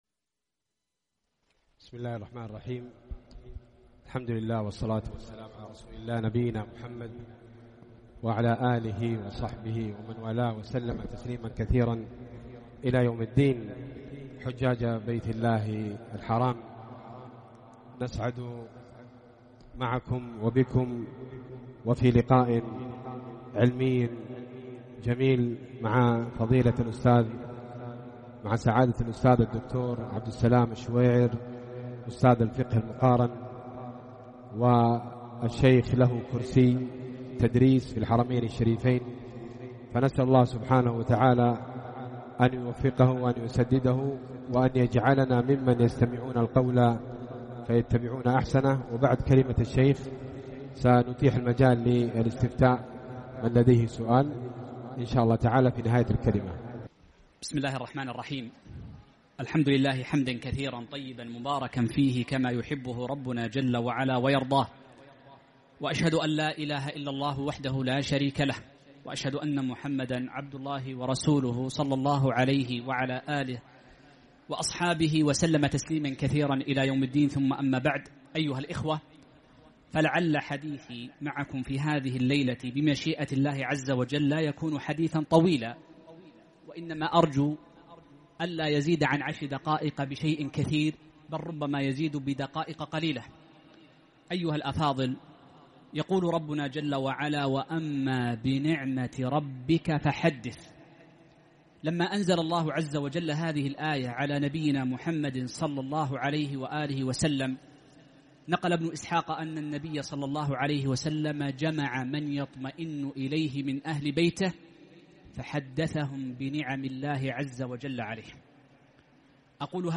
ألقي في الحج لعام 1443 في شركة الحج